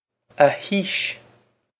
Pronunciation for how to say
a heesh
This is an approximate phonetic pronunciation of the phrase.